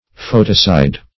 Foeticide \F[oe]"ti*cide\, n.